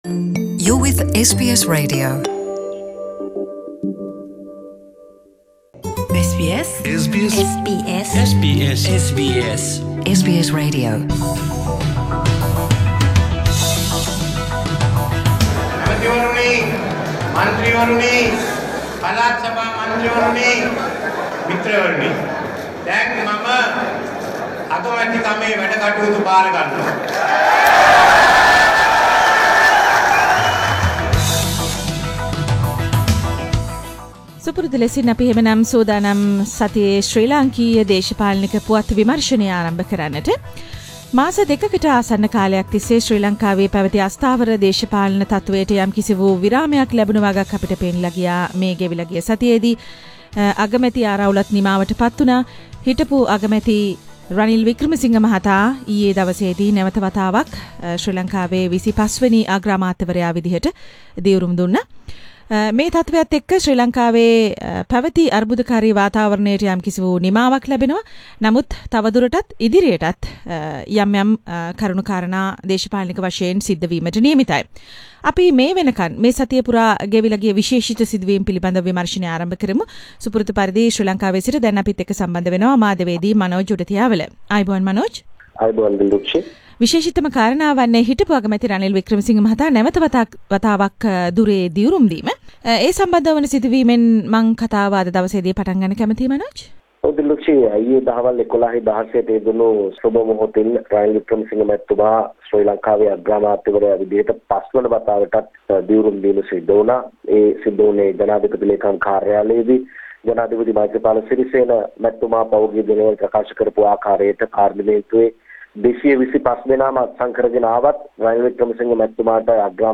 අද හෙටම නව කැබිනට් මණ්ඩලය පත් කෙරෙන අතරේ එජනිස යෙන් පිරිසක් එජාපය ට පනින්න සූදානම් :සතියේ දේශපාලන පුවත් සමාලෝචනය